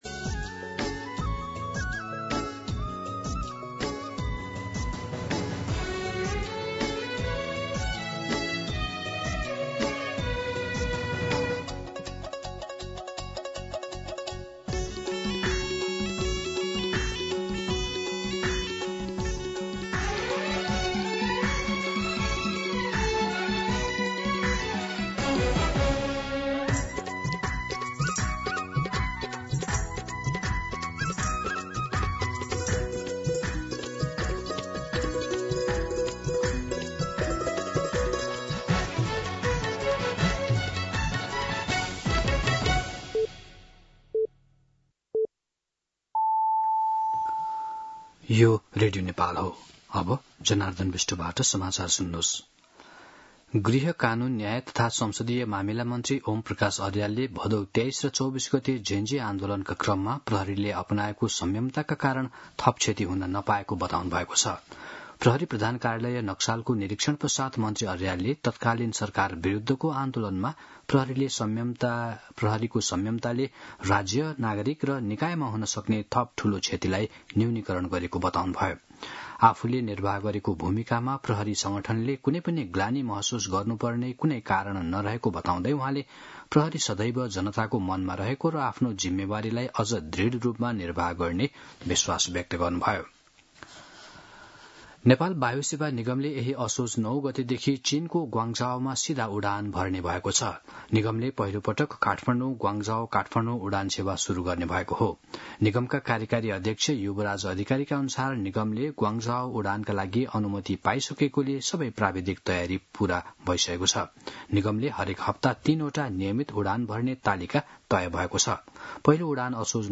मध्यान्ह १२ बजेको नेपाली समाचार : ५ असोज , २०८२